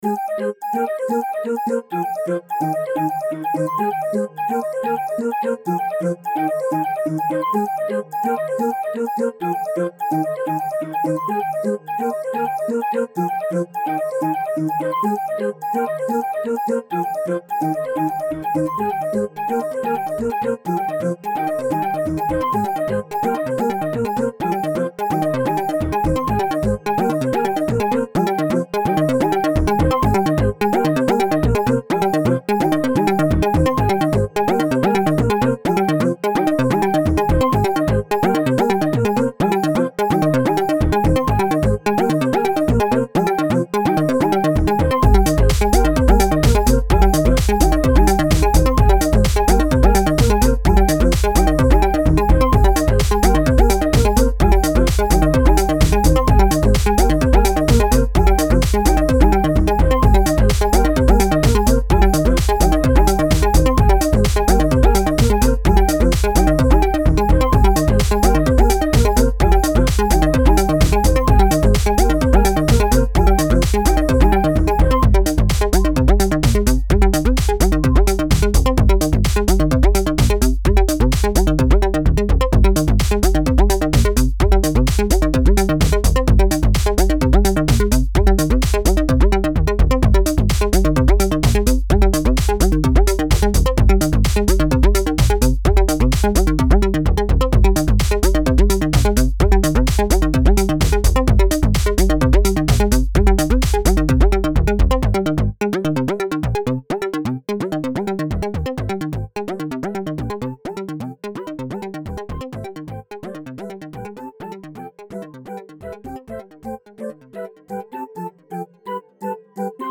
Home > Music > Electronic > Bright > Running > Chasing